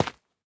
Minecraft Version Minecraft Version 25w18a Latest Release | Latest Snapshot 25w18a / assets / minecraft / sounds / block / dripstone / step4.ogg Compare With Compare With Latest Release | Latest Snapshot
step4.ogg